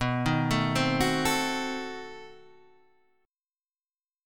B7b9 chord {x 2 1 2 1 2} chord